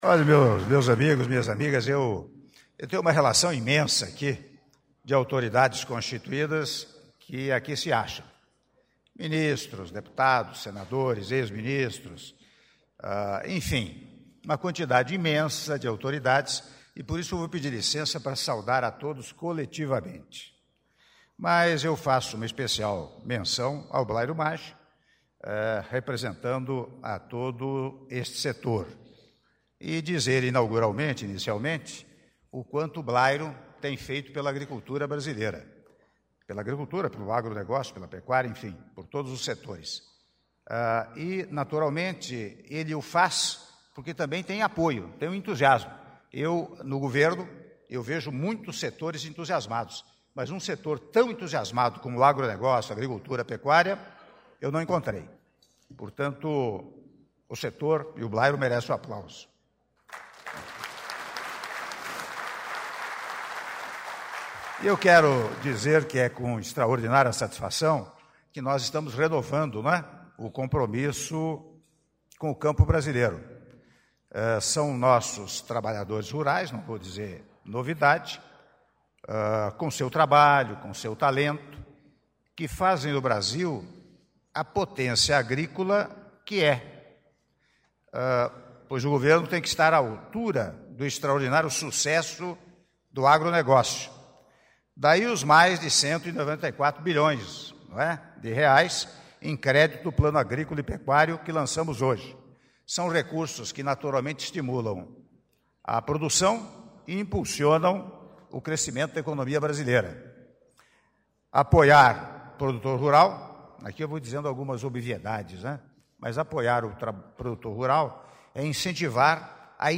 Áudio do discurso do Presidente da República, Michel Temer, durante a cerimônia de Lançamento do Plano Agrícola e Pecuário 2018/2019 -Brasília/DF- (07min09s)